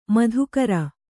♪ madhukara